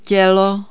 [c]
Czech tělo [celo] ‘body’ vs. [ɟ] Czech dělo [ɟelo] ‘gun’
Czech-body.wav